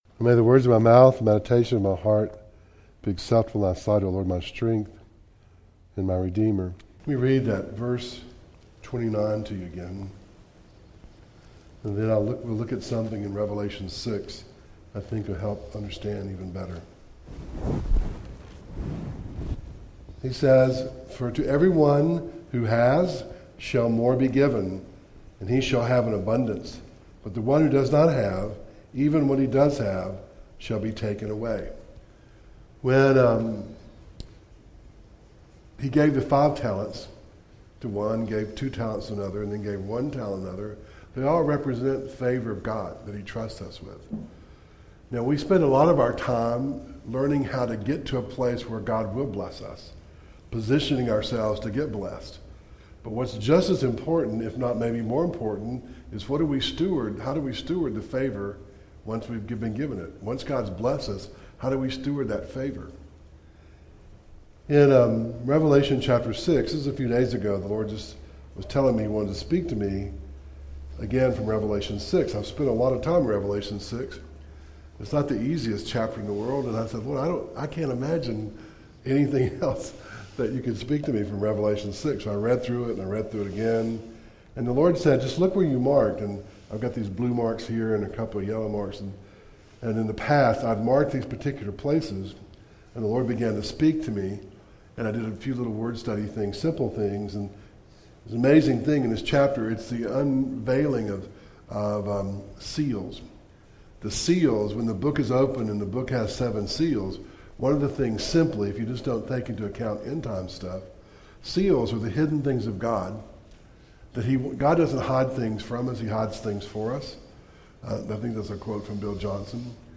Acts 1:1-14 Service Type: Sunday Morning